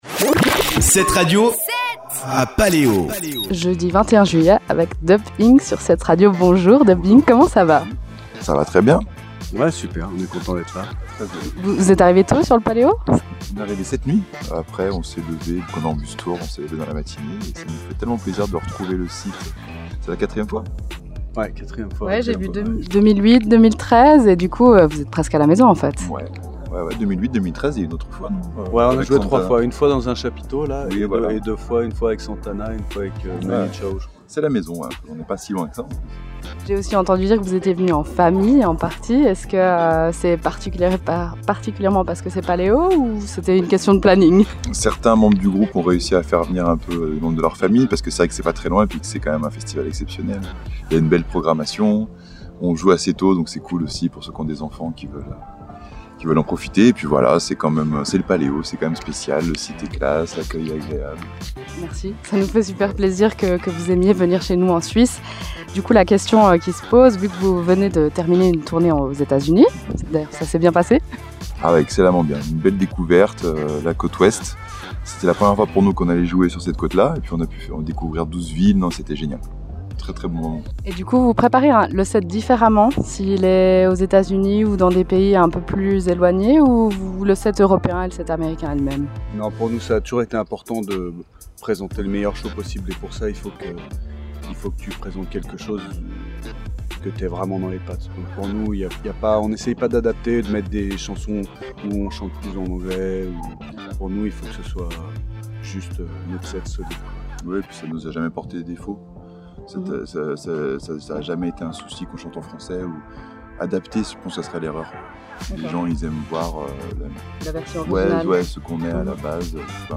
Paléo 2022 – Interview Dub Inc
Paleo22-Dubinc-Itw.mp3